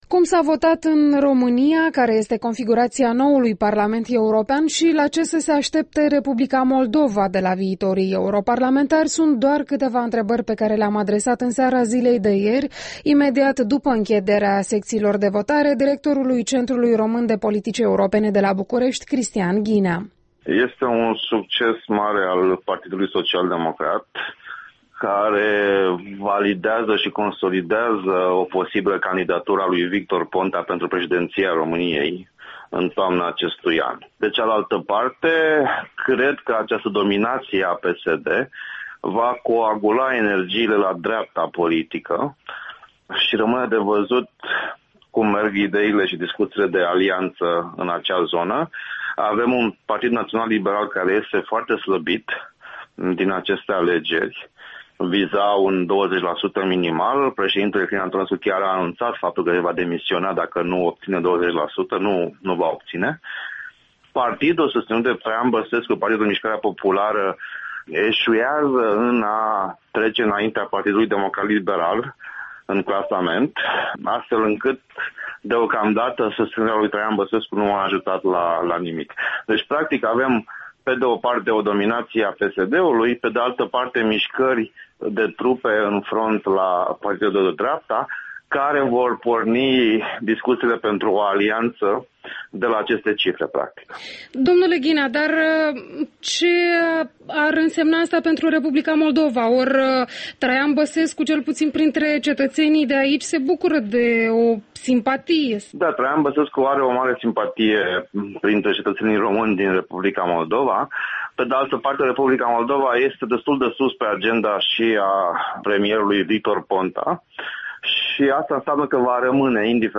Interviul dimineții: despre alegerile europene cu Cristian Ghinea